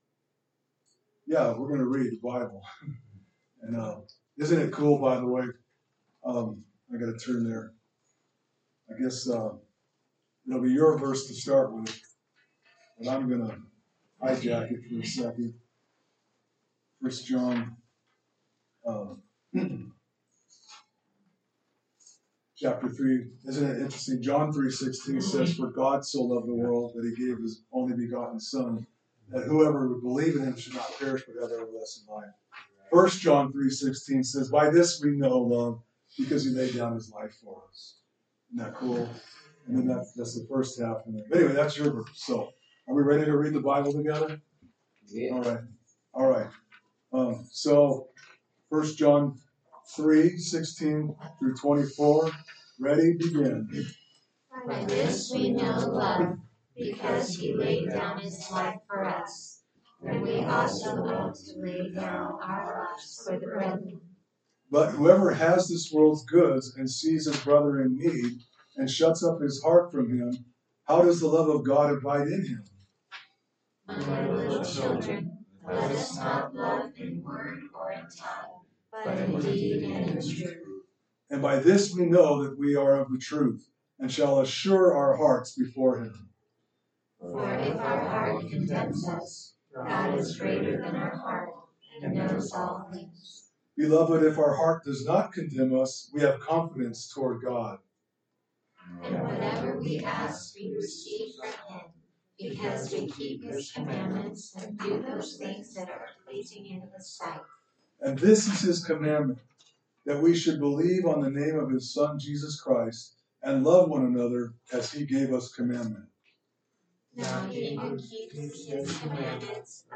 A message from the series "1 John."